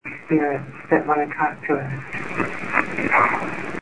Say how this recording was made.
All are Class C to B quality voices. The following have been amplified and spikes from the recorder have been reduced: